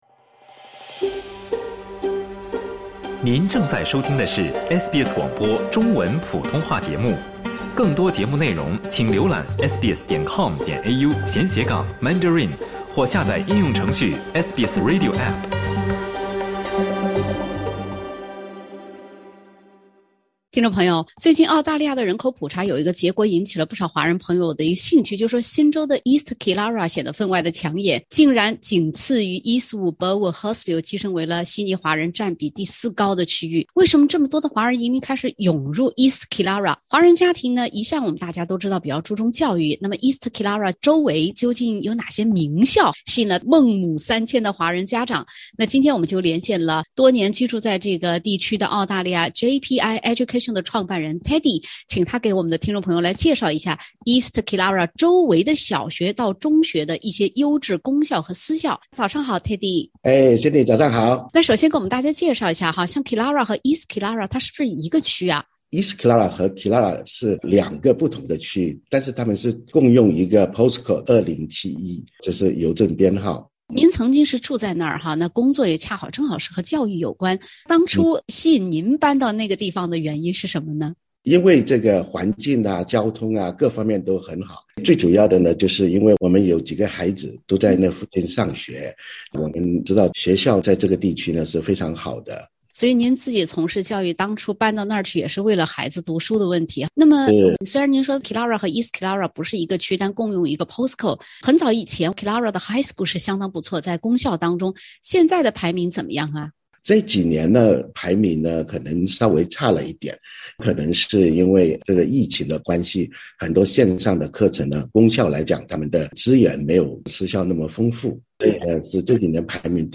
East killara周围的“名校”是否吸引了大量“孟母三迁”的华人家长呢？（点击封面图片，收听完整对话）